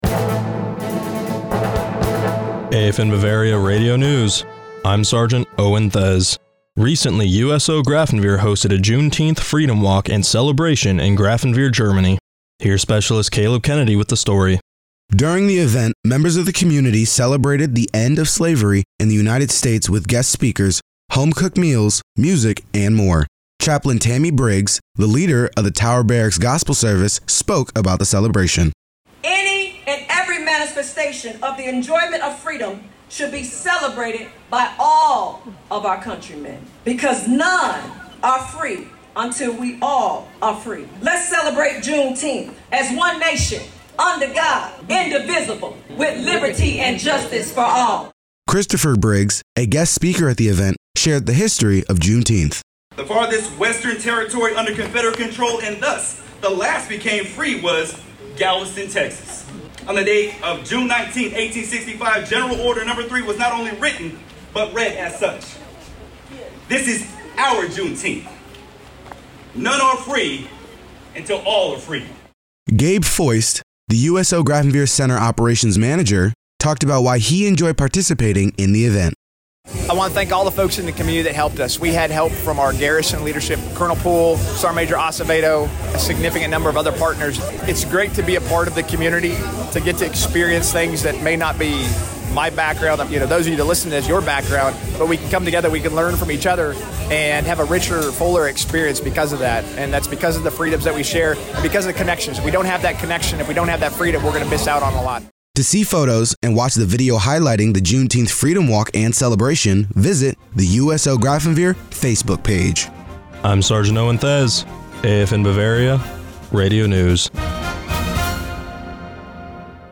AFN Bavaria Radio News June 24, 2024